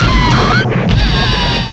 cry_not_heatran.aif